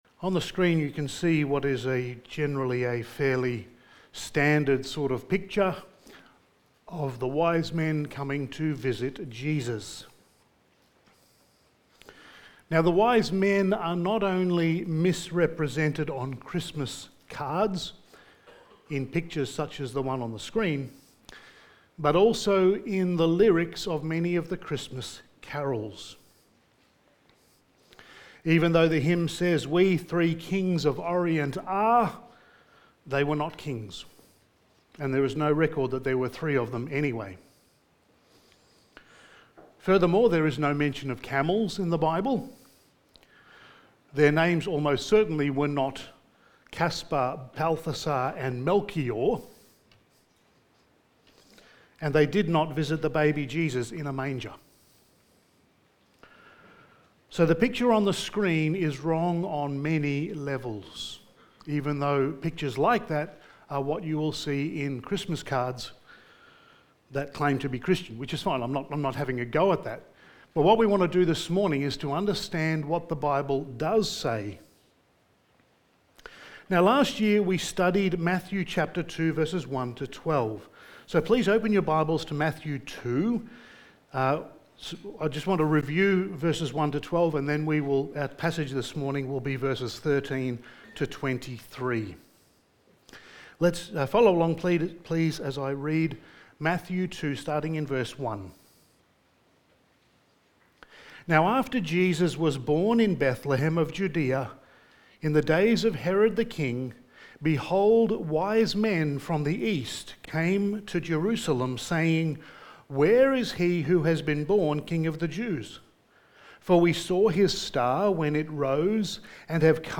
Passage: Matthew 2:13-23 Service Type: Special Event